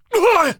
B_pain_1.ogg